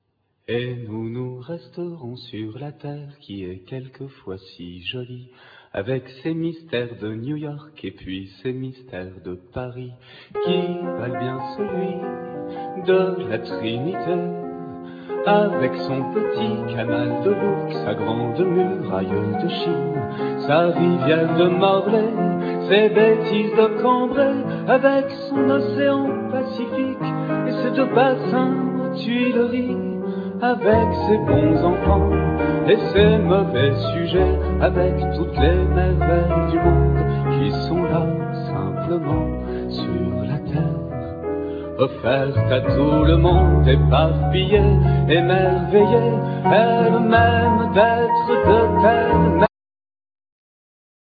Live enregistre aux Francofolies de La Rochelle 2000
Vocals
Piano
Contrabass
Drums
1st Violin
Viola
Cello